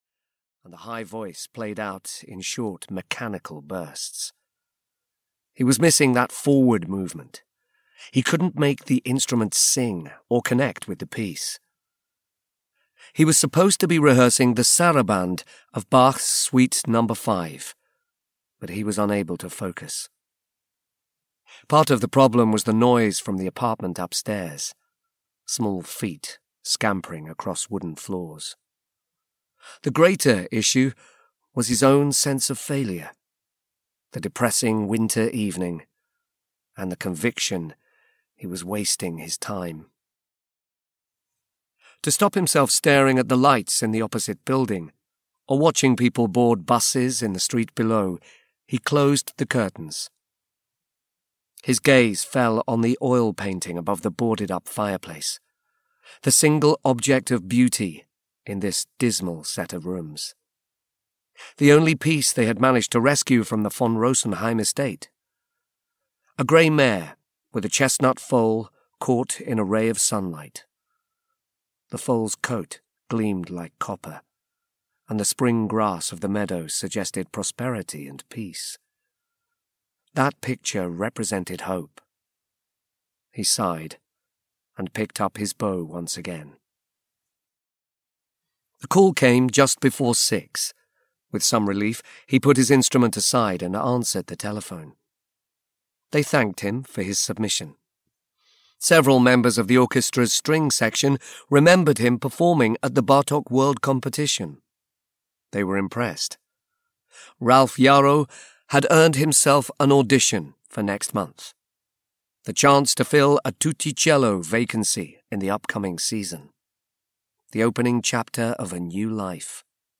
Wolf Tones (EN) audiokniha
Ukázka z knihy